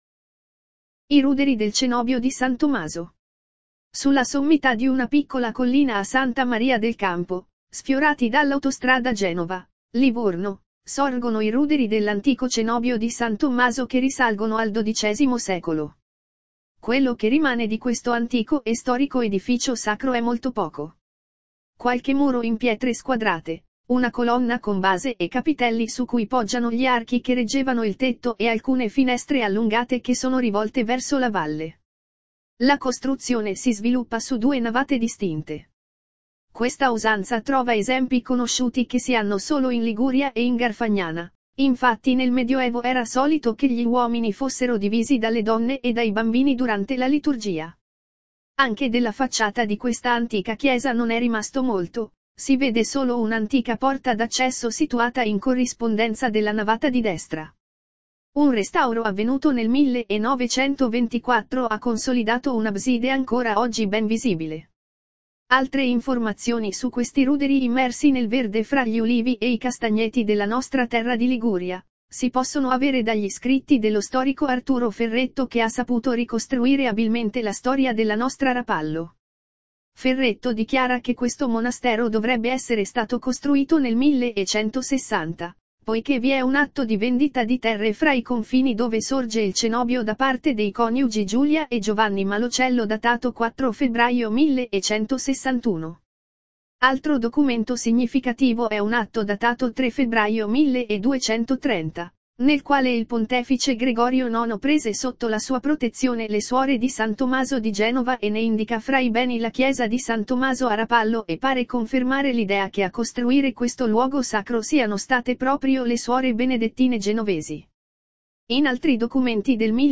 Per chi non può o non vuole leggere tutto il post è disponibile la sua registrazione audio.